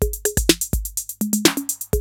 TR-808 LOOP2 1.wav